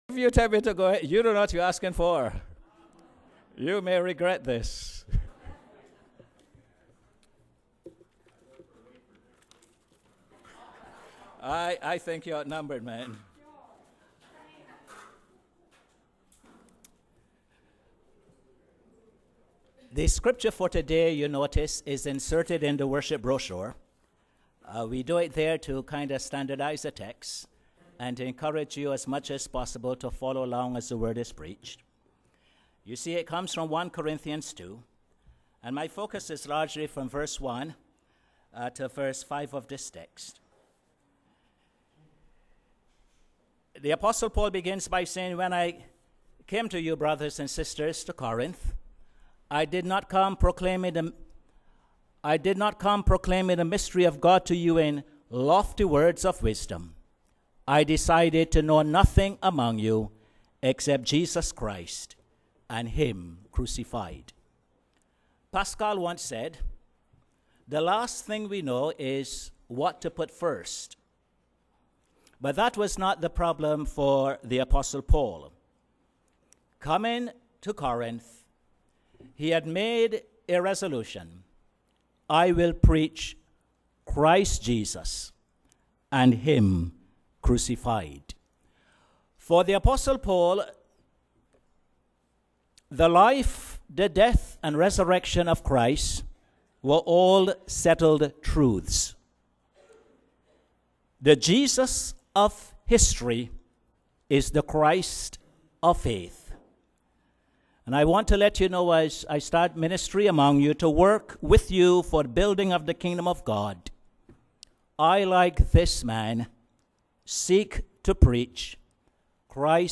Posted in Sermons on 12.